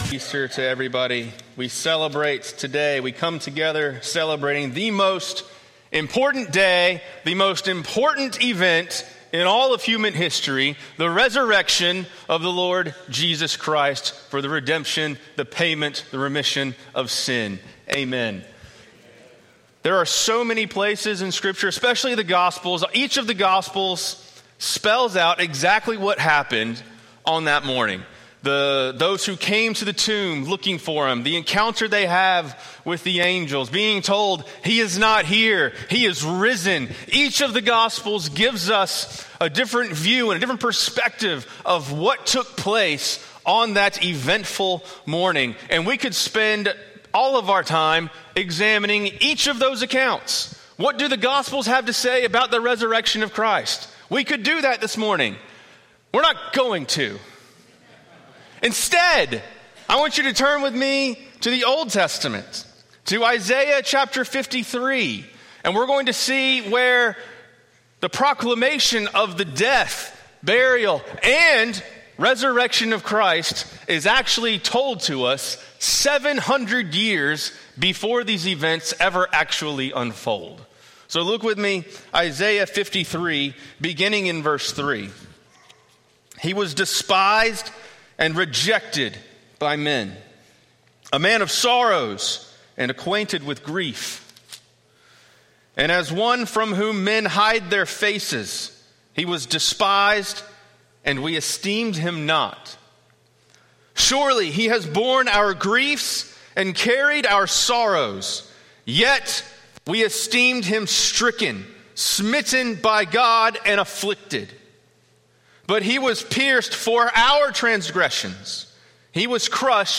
Easter Sermons «